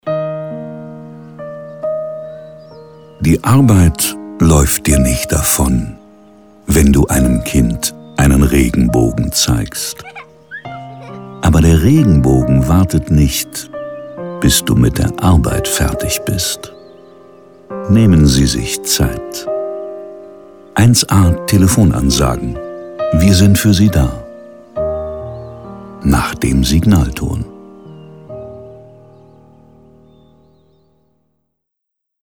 Kreative Telefonansage
Telefonansagen mit echten Stimmen – keine KI !!!
Beispiel 9. mit der deutschen Synchronstimme von Richard Gere
1a-telefonansagen-Richard-Gere-Regenbogen.mp3